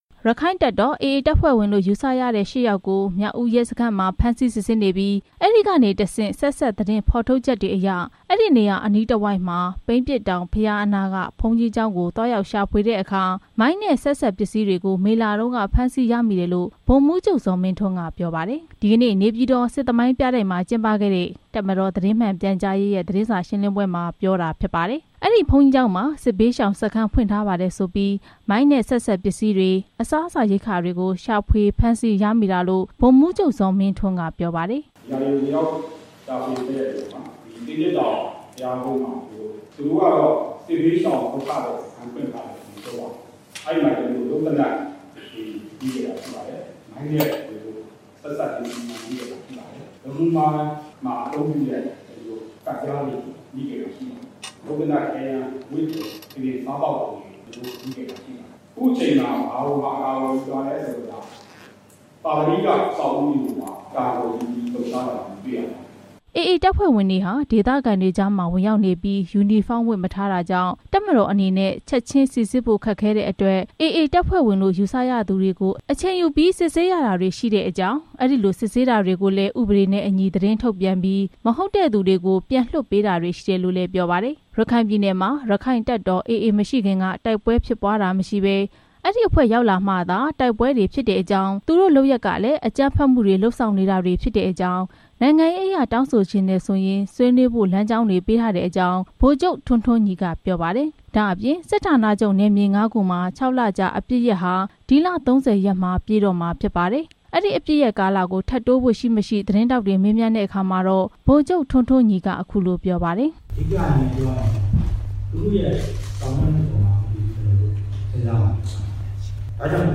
နေပြည်တော်မှာ ဒီနေ့ကျင်းပတဲ့ တပ်မတော် သတင်းမှန်ပြန်ကြားရေးအဖွဲ့ရဲ့ သတင်းစာရှင်းလင်းပွဲမှာ ဗိုလ်ချုပ် ထွန်းထွန်းညီက ပြောလိုက်တာဖြစ်ပါတယ်။